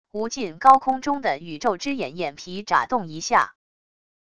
无尽高空中的宇宙之眼眼皮眨动一下wav音频